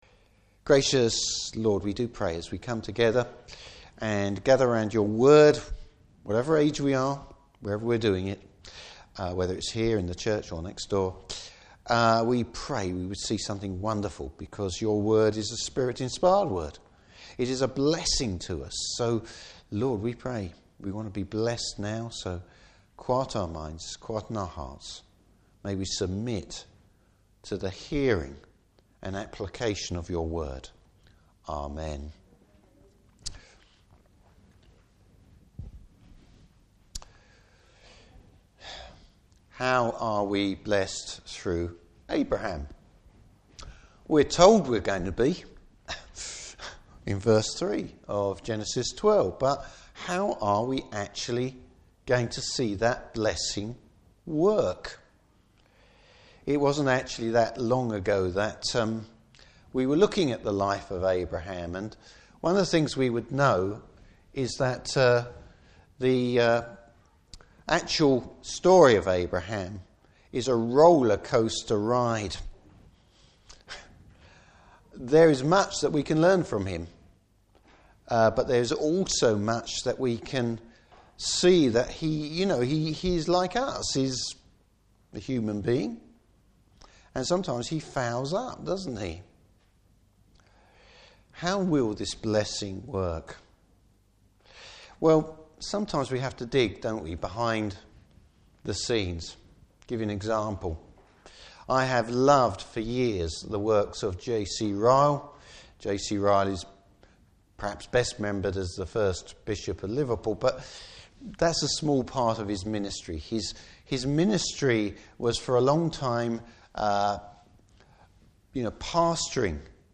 Service Type: Morning Service The encouragement to us in God’s promise to Abraham.